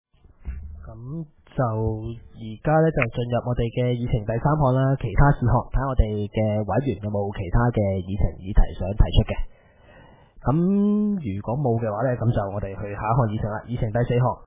委員會會議的錄音記錄